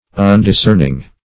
Undiscerning \Un`dis*cern"ing\, n.